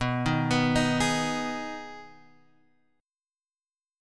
Abm/Cb Chord